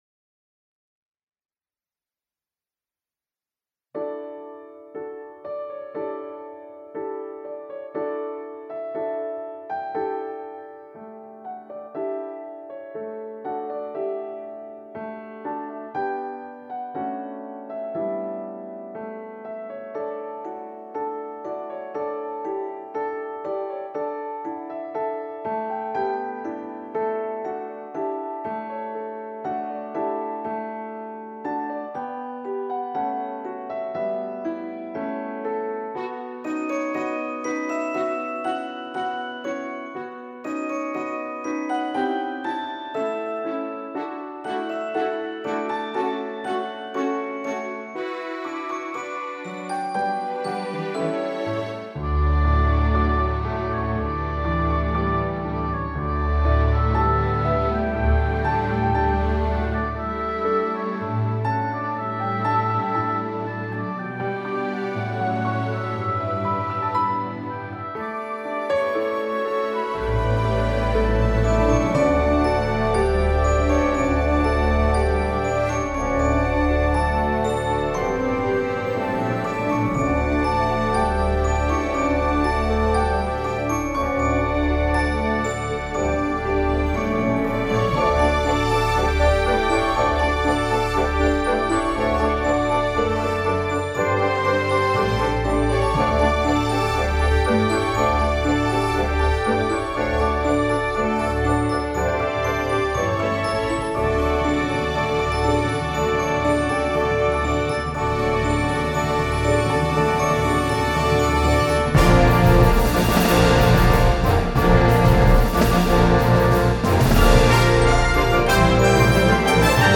This demo relies on "audio mix".